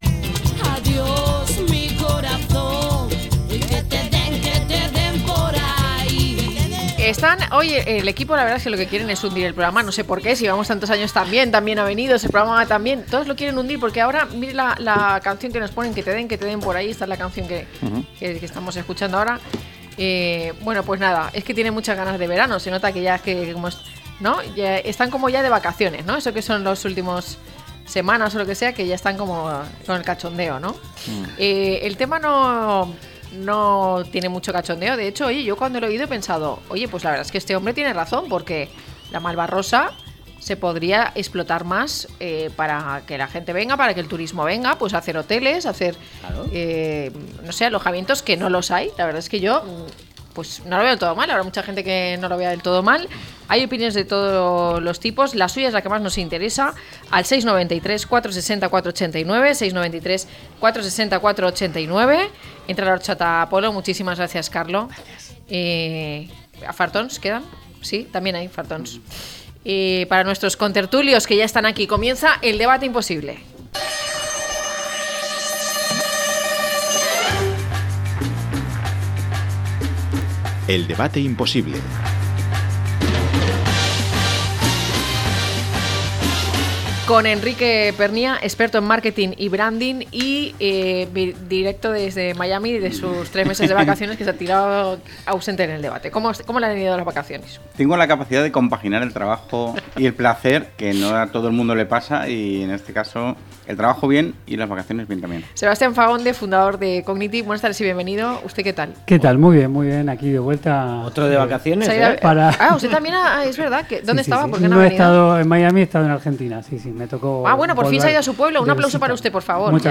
Institutos, hospitales y vivienda social u hoteles de 5 estrellas, a debate - La tarde con Marina